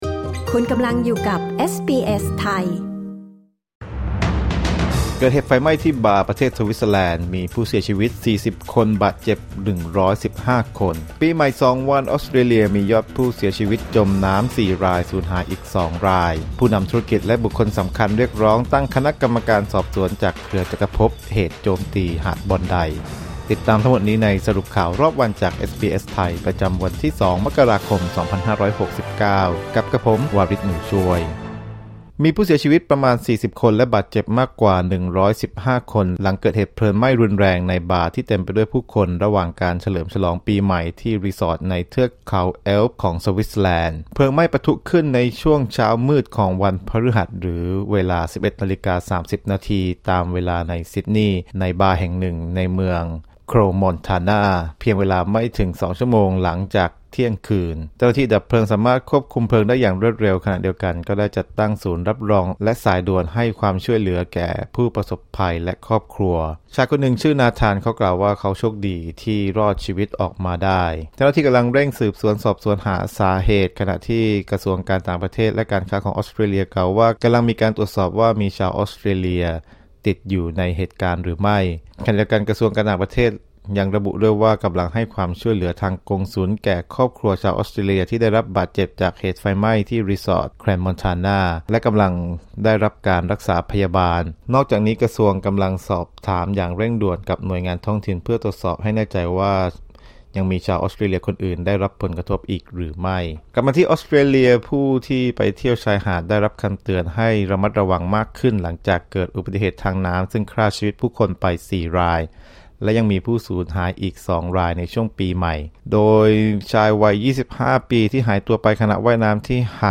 สรุปข่าวรอบวัน 2 มกราคม 2569